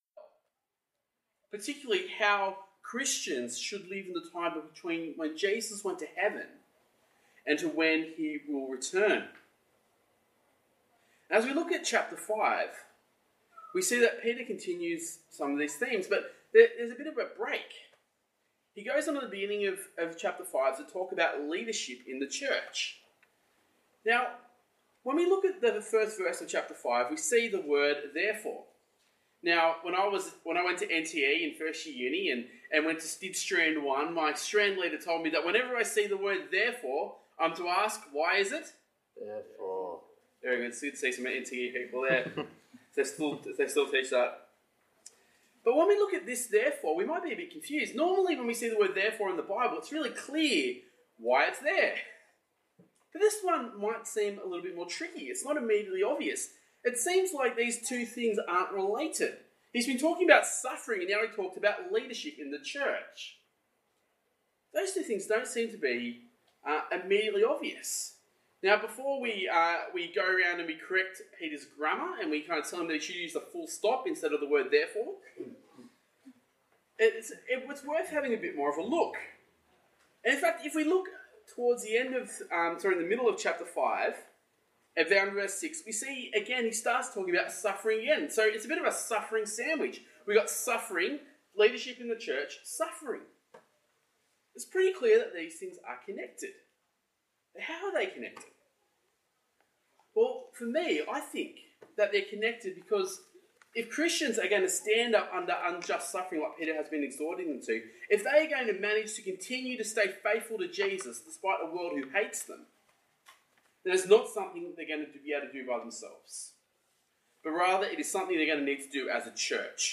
Talk Type: Getaway